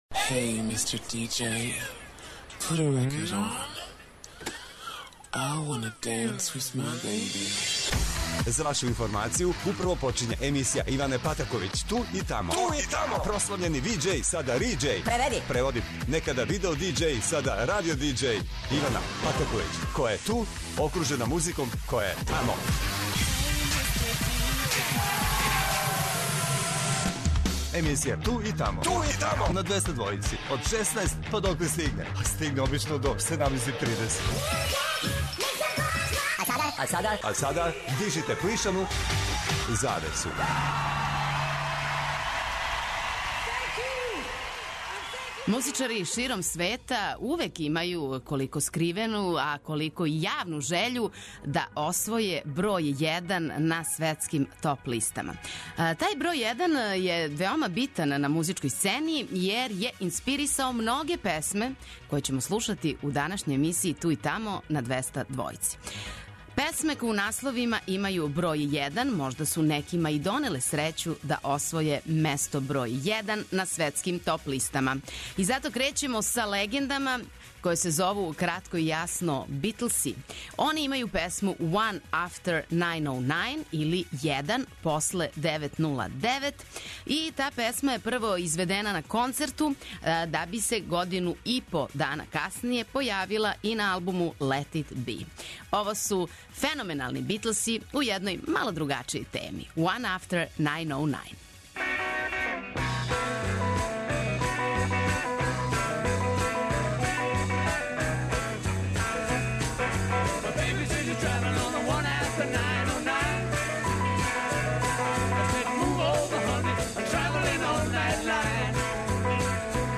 У новој емисији 'Tу и тамо' све је у знаку броја један. Слушаћемо песме које у насловима имају јединицу и то на Двестадвојци!!!